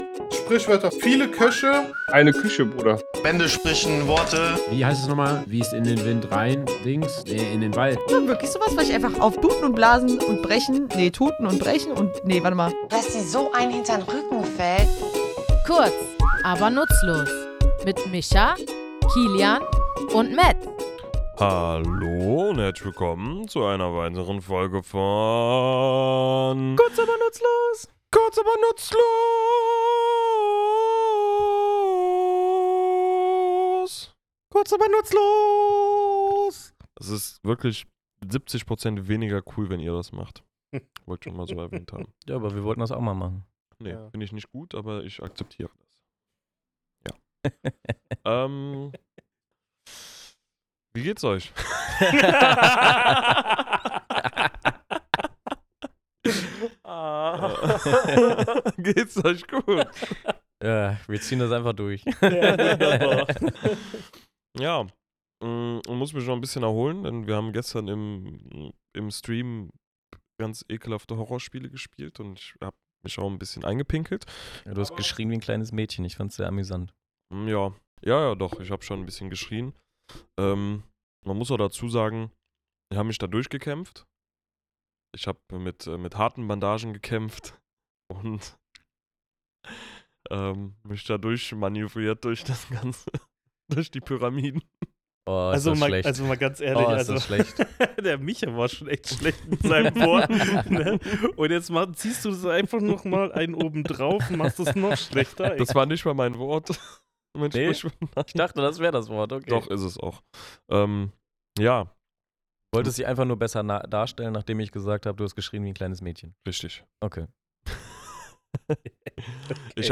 Was bedeutet es, wenn jemand kompromisslos und mit voller Härte vorgeht, und woher stammt dieser kampflustige Ausdruck? Wir, drei tätowierende Sprachenthusiasten, sprechen in unserem Tattoostudio über die Herkunft und Bedeutung dieser Redensart – und zeigen, warum die deutsche Sprache manchmal genauso direkt zuschlägt wie ein Faustkampf.